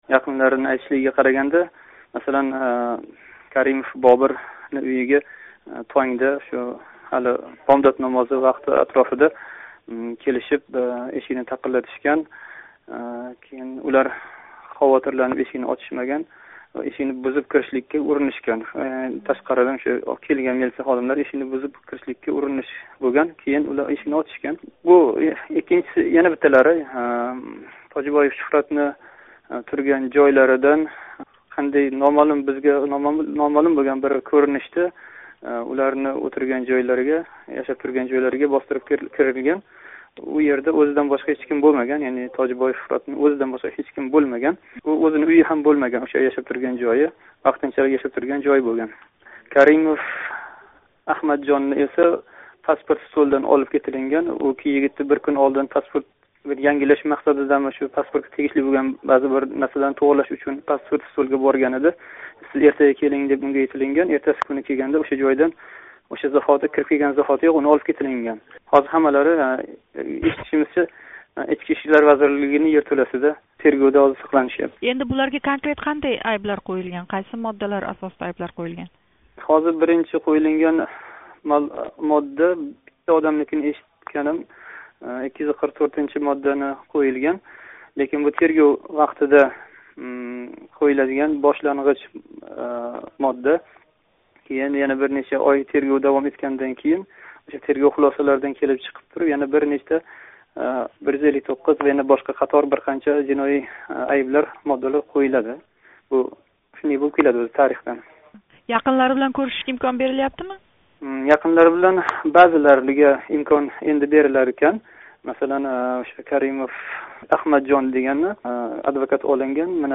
суҳбат.